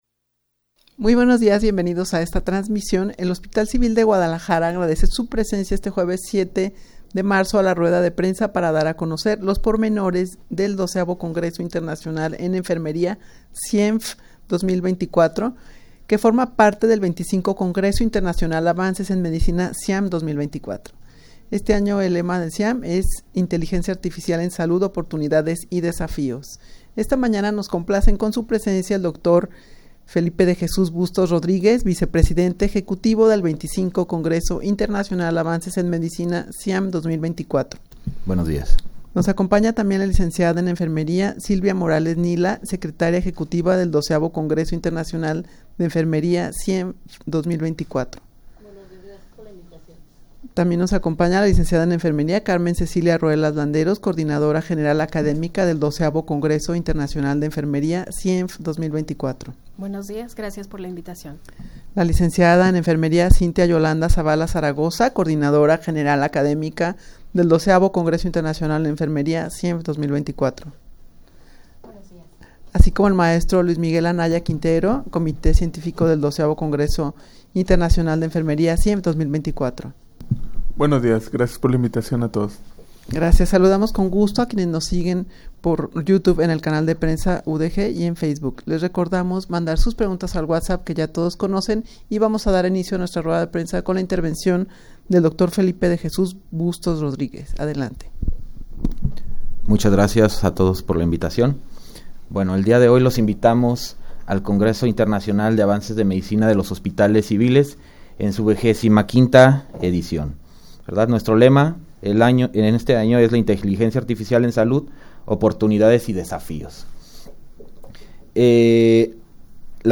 Audio de la Rueda de Prensa
rueda-de-prensa-para-dar-a-conocer-los-pormenores-del-xii-congreso-internacional-de-enfermeria-cienf.mp3